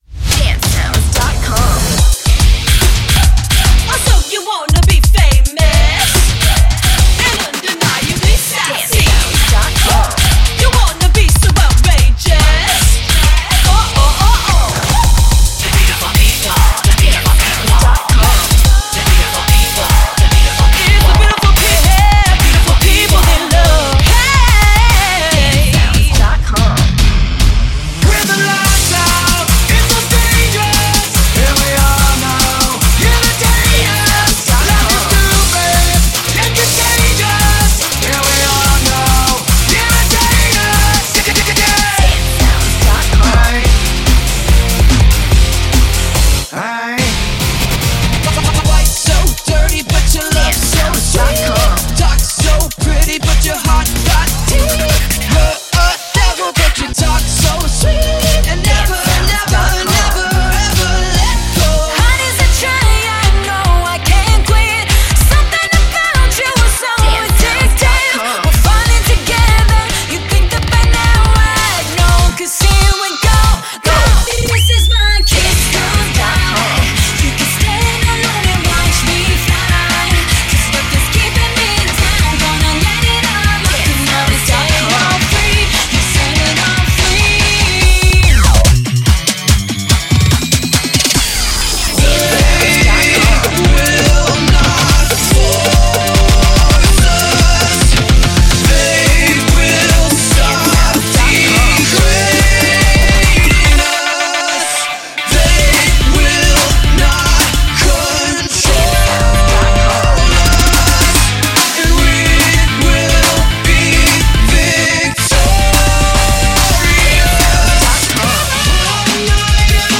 Premade Dance Music Mix